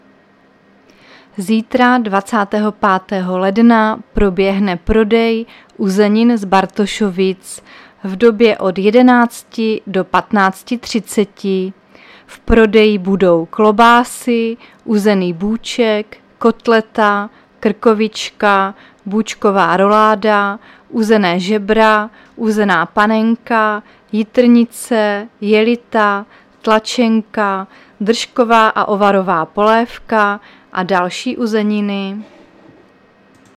Záznam hlášení místního rozhlasu 24.1.2023